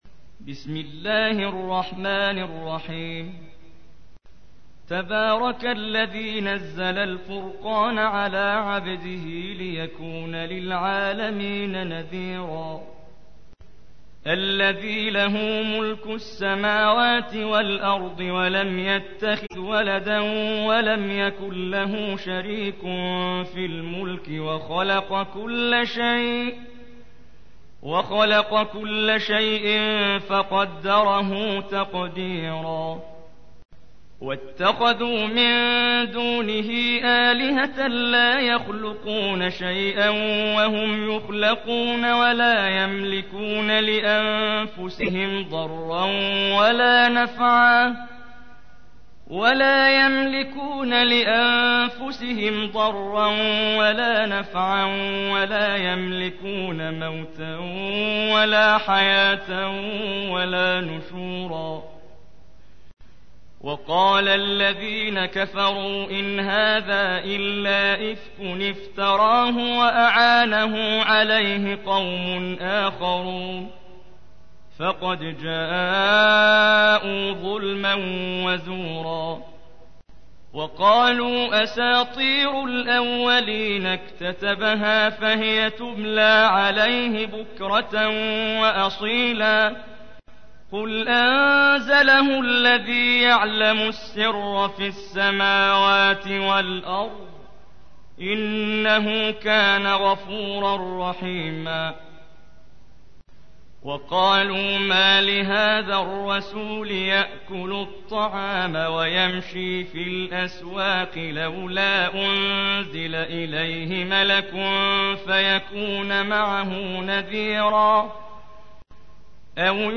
تحميل : 25. سورة الفرقان / القارئ محمد جبريل / القرآن الكريم / موقع يا حسين